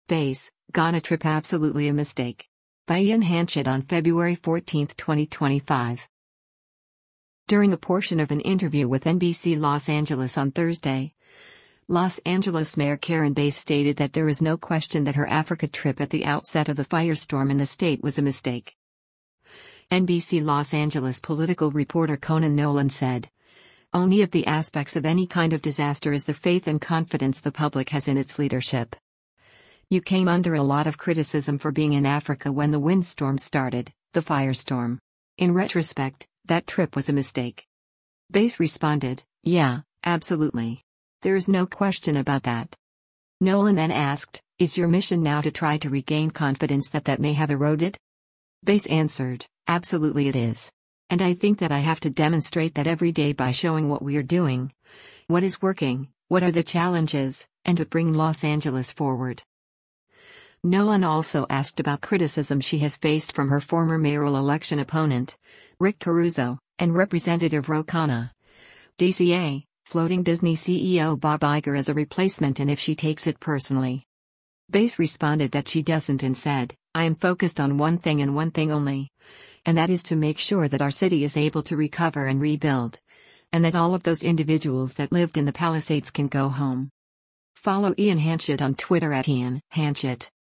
During a portion of an interview with NBC Los Angeles on Thursday, Los Angeles Mayor Karen Bass stated that “There is no question” that her Africa trip at the outset of the firestorm in the state was a mistake.